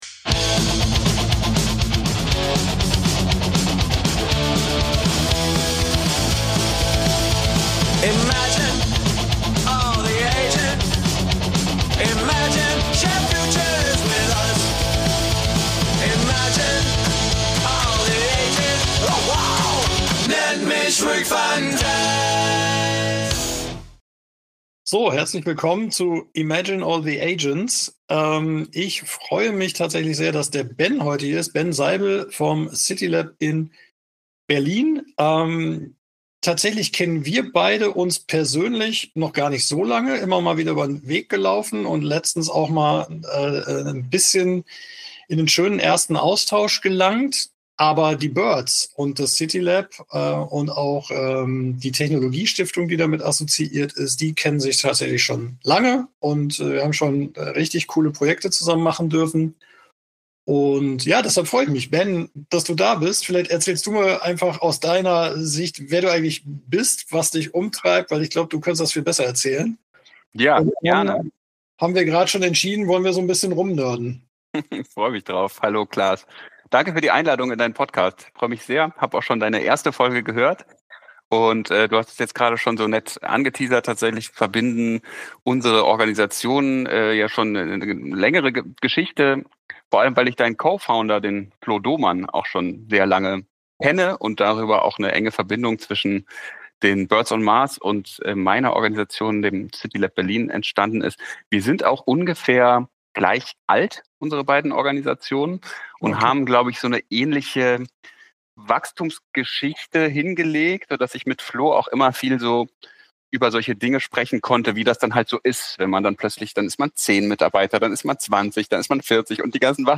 Ein intensives Gespräch über hybride Realitäten von morgen und darüber, warum KI nie wieder so schlecht sein wird wie heute.